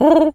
Animal_Impersonations
pigeon_call_calm_03.wav